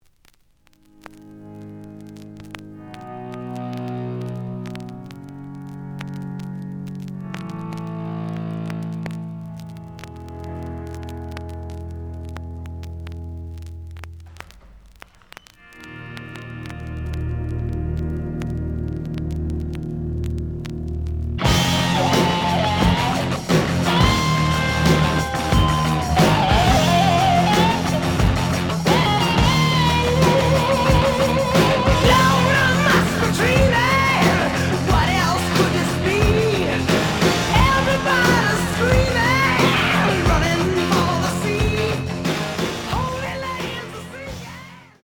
試聴は実際のレコードから録音しています。
●Genre: Rock / Pop
盤に若干の歪み。